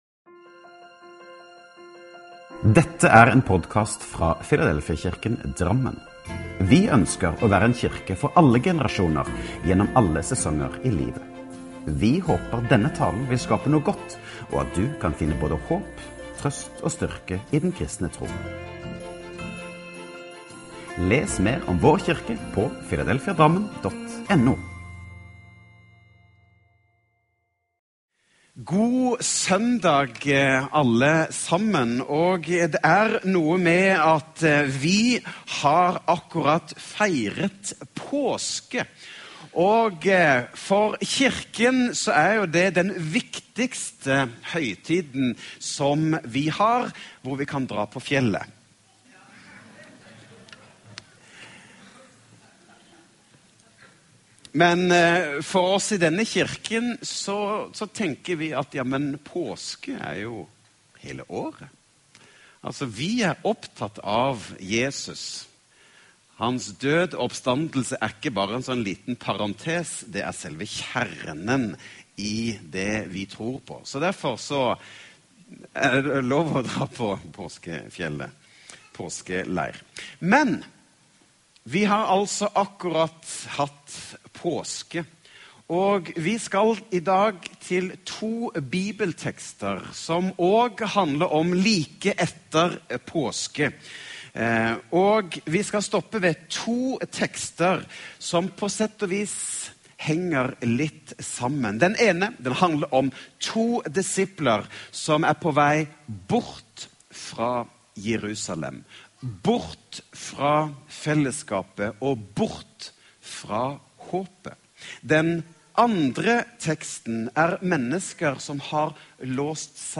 Last ned talen til egen maskin eller spill den av direkte: Taler